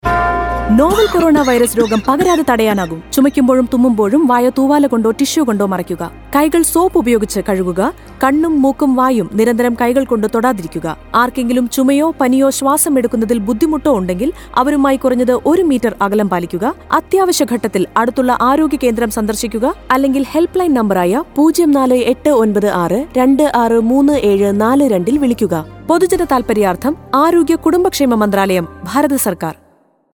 Radio PSA
5153_Cough Radio_Malayalam_Lakshadweep.mp3